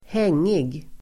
Uttal: [²h'eng:ig]